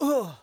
xys被击倒4.wav 0:00.00 0:00.45 xys被击倒4.wav WAV · 39 KB · 單聲道 (1ch) 下载文件 本站所有音效均采用 CC0 授权 ，可免费用于商业与个人项目，无需署名。
人声采集素材